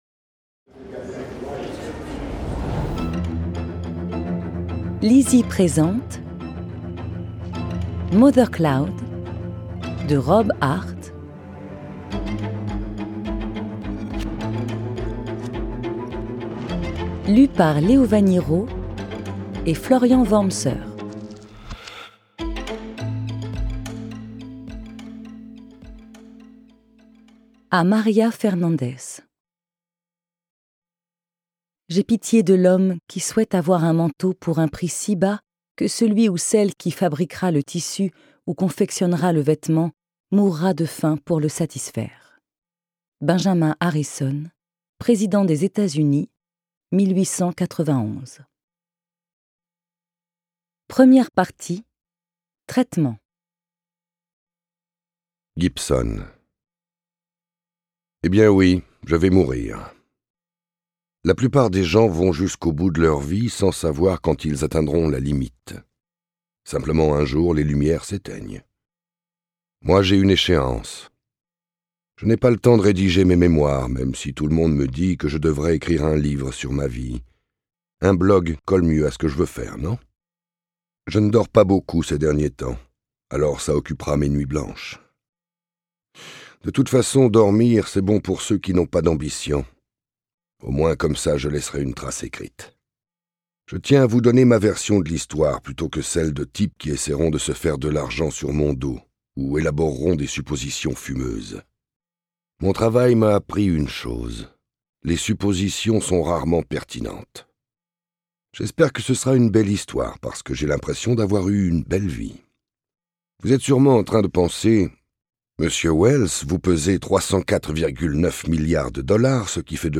Click for an excerpt - Mothercloud de Rob HART